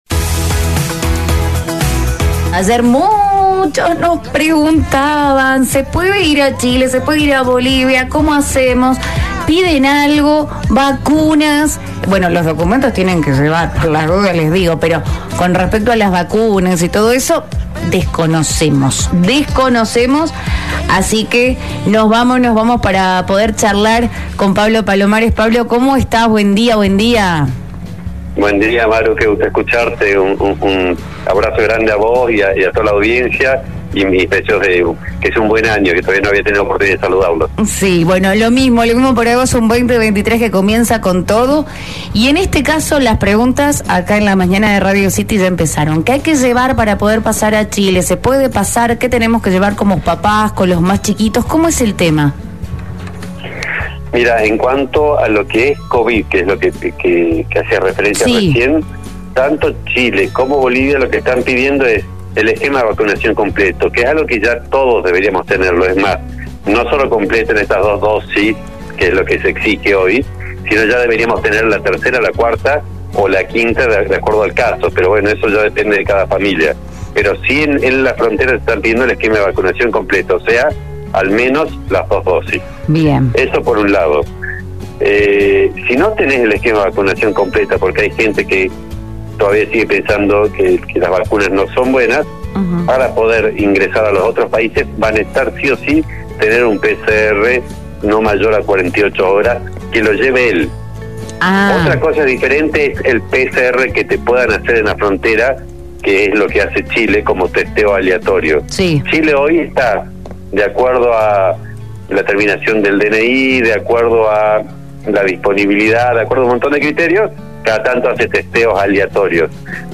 En el podcast de hoy dialogamos con Pablo Palomares, Secretario de Relaciones Internacionales para conocer los detalles en cuanto a todos los requerimientos necesarios para que los viajeros puedan pasar al vecino país de Chile con total tranquilidad.